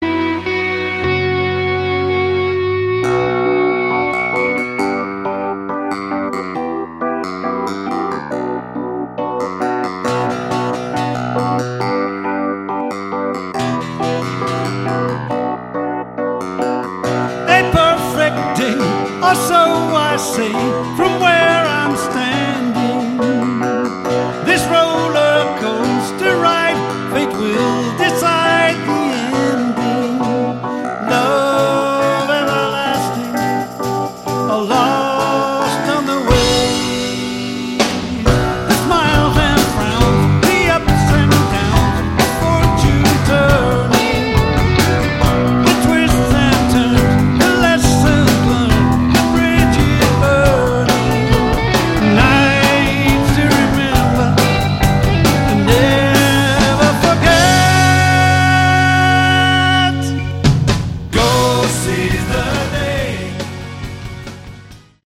Category: Prog Rock
bass, vocals
drums
guitars
keyboards